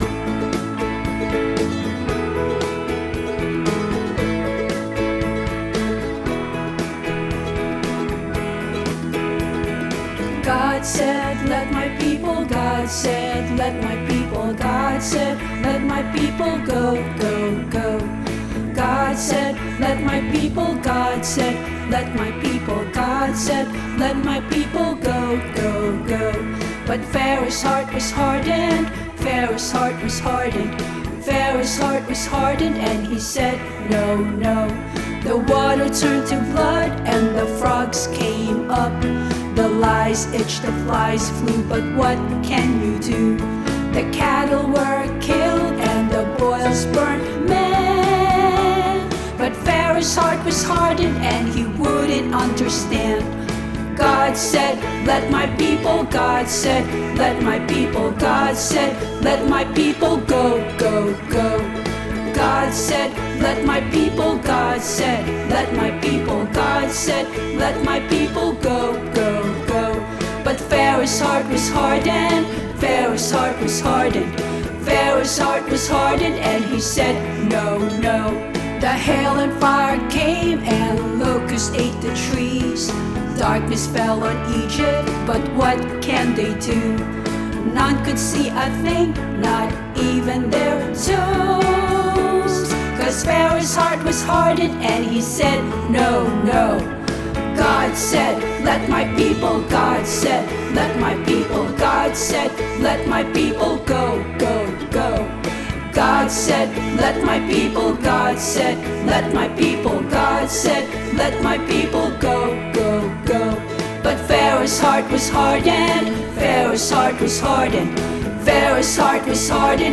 song-let-my-people-go-a-childrens-song.m4a